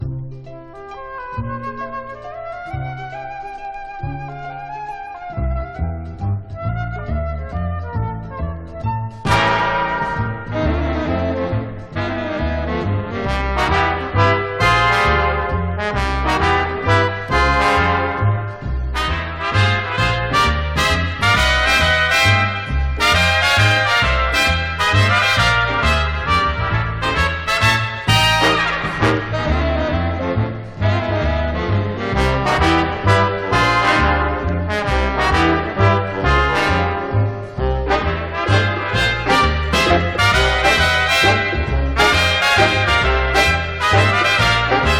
Jazz, Swing, Easy Listening　USA　12inchレコード　33rpm　Mono